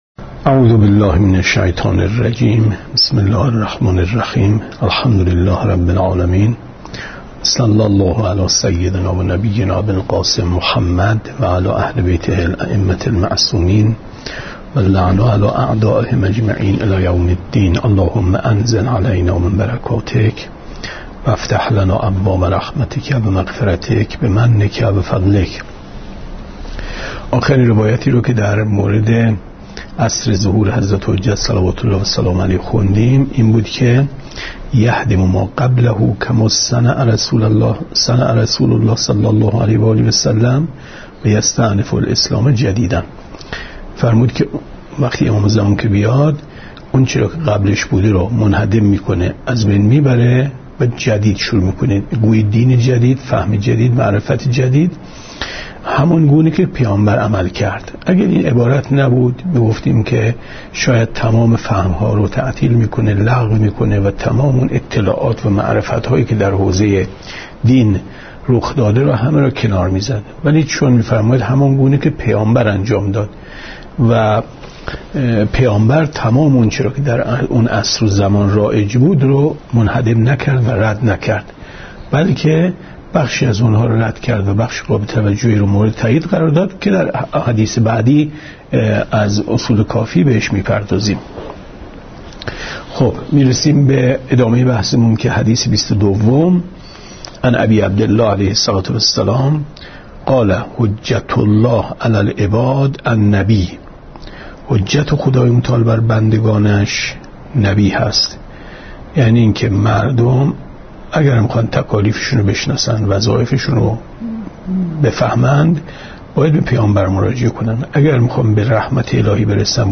گفتارهای ماه مبارک رمضان 1436 ـ جلسه بیستم ـ 24/ 4/ 94 ـ شب بیست و نهم ماه رمضان